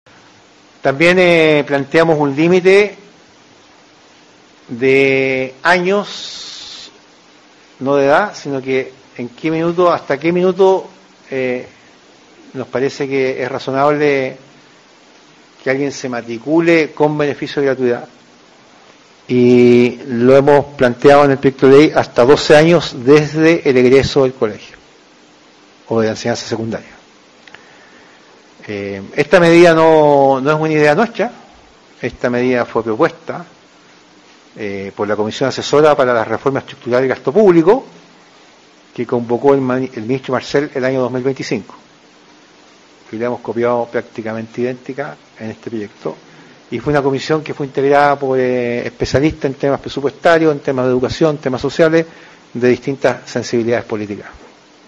Durante un punto de prensa realizado este miércoles, el ministro también respondió a las críticas formuladas por el excandidato presidencial del Partido de la Gente, Franco Parisi, y por parlamentarios de esa colectividad, quienes pidieron medidas más focalizadas para la clase media.